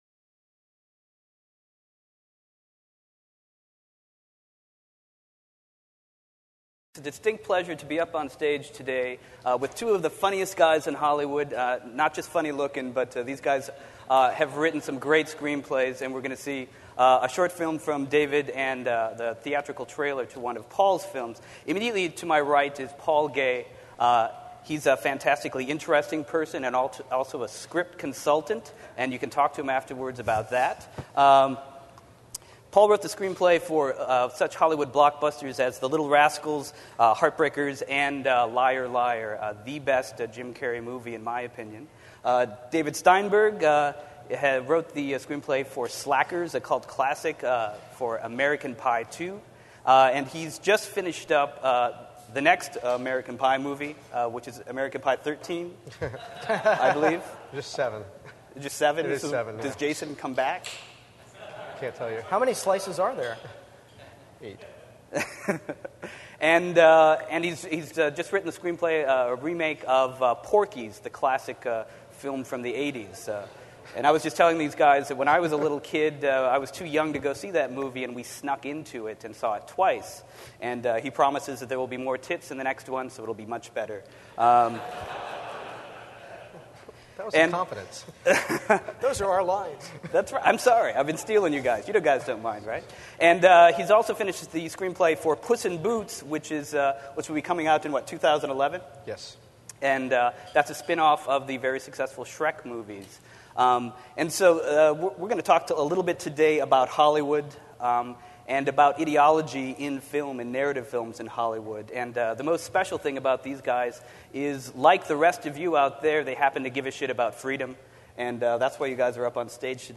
At Reason Goes Hollywood, our 40th anniversary bash held November 14-15, 2008 in Los Angeles